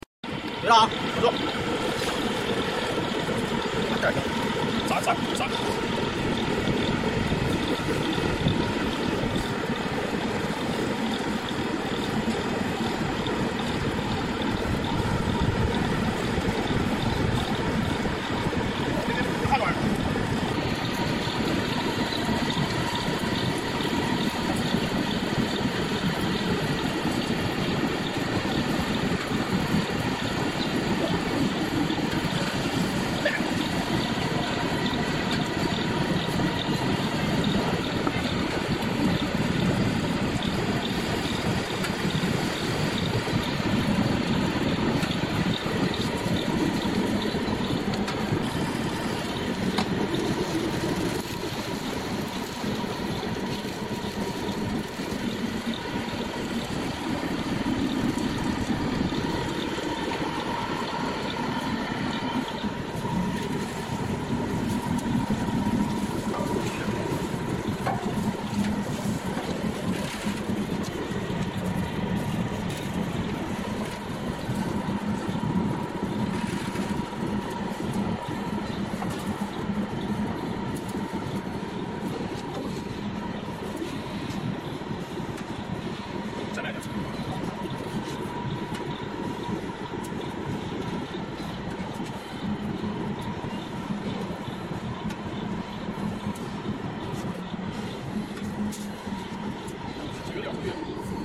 hot pot residue recycle machine